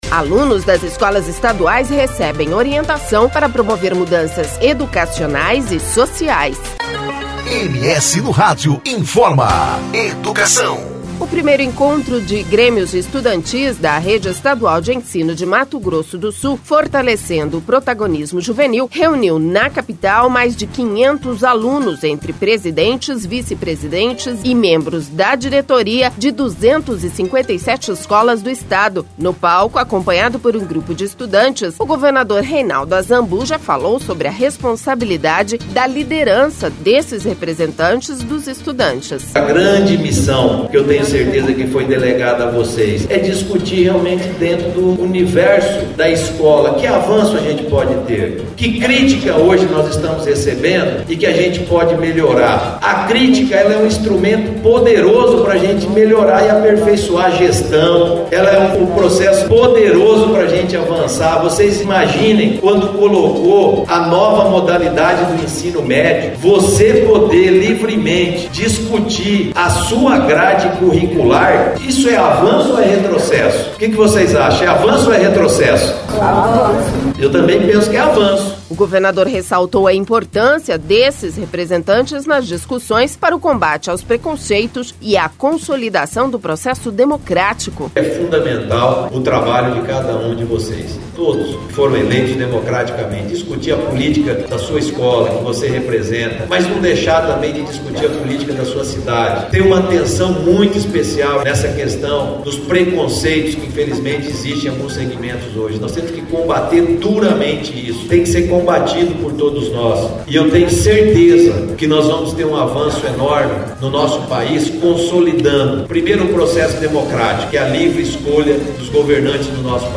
O primeiro Encontro dos Grêmios Estudantis da Rede Estadual de Ensino de Mato Grosso do Sul – Fortalecendo o Protagonismo Juvenil, reuniu na Capital mais de 500 alunos, entre presidentes, vice-presidentes, e membros da diretoria, de 257 escolas do Estado.
No palco, acompanhado por um grupo de estudantes o Governador Reinaldo Azambuja falou sobre a responsabilidade da liderança desses representantes dos estudantes.